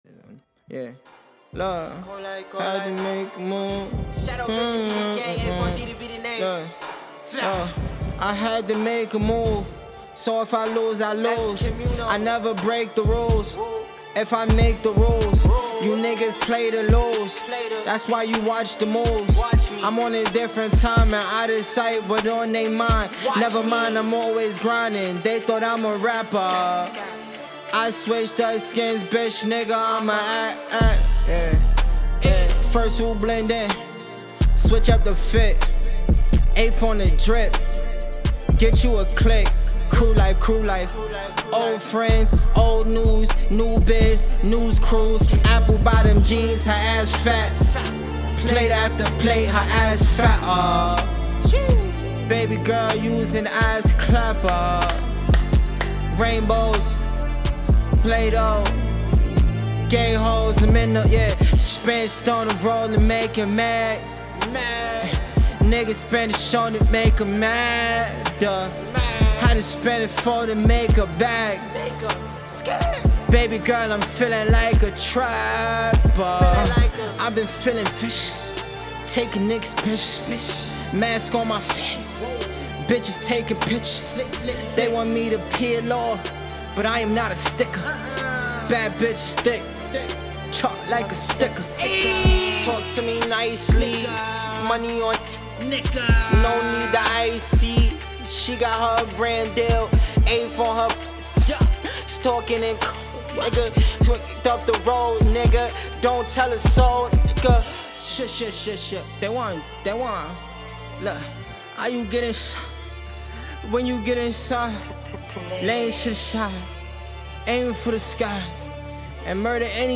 BACKGROUND+NOISE.m4a